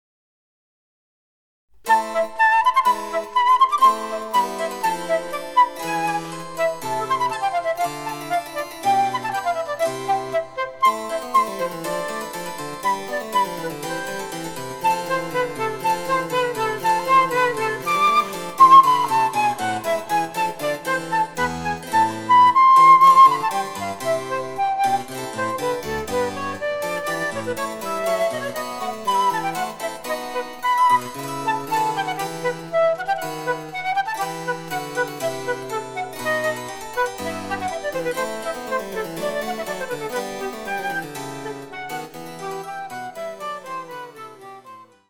■フルートによる演奏
チェンバロ（電子楽器）